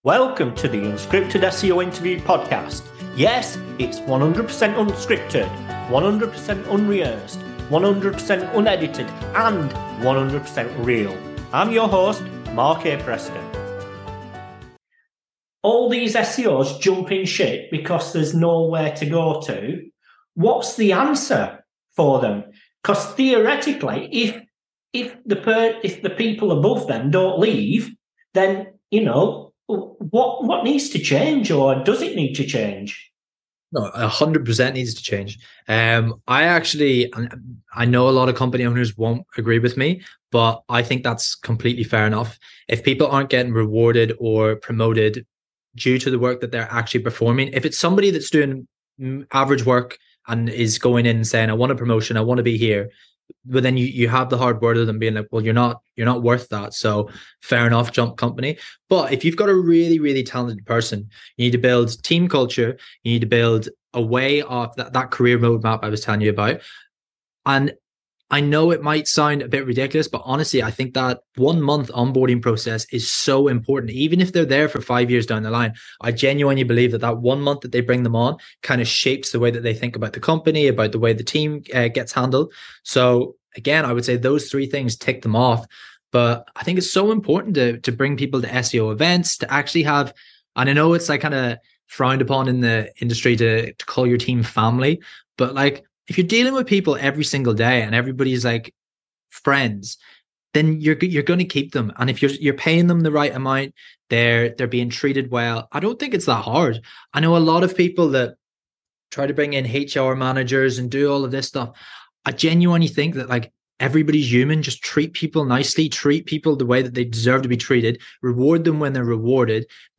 In a heart-to-heart conversation, they share their beliefs that fostering a nurturing and friendly environment can indeed be a powerful retention strategy.